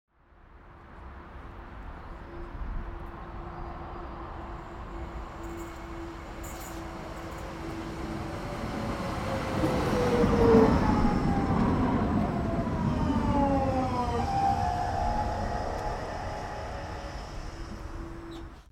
دانلود آهنگ شب 16 از افکت صوتی طبیعت و محیط
دانلود صدای شب 16 از ساعد نیوز با لینک مستقیم و کیفیت بالا
جلوه های صوتی